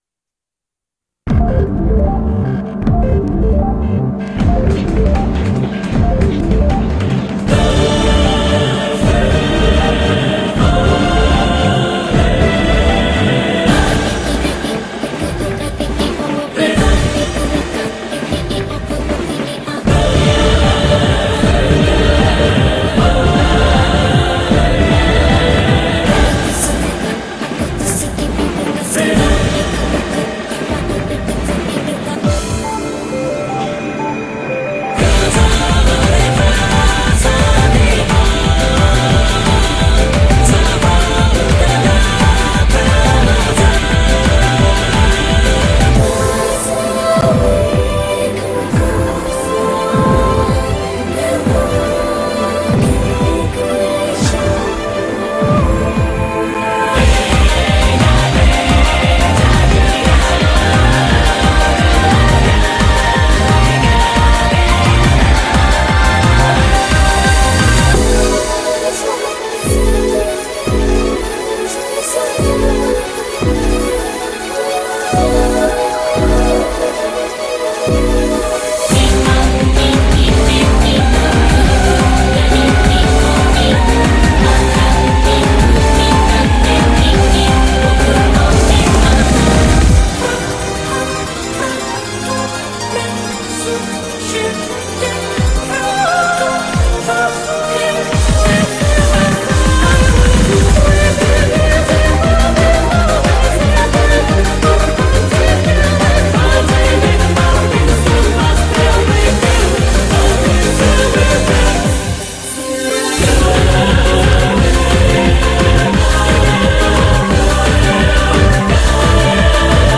BPM155-175
Audio QualityCut From Video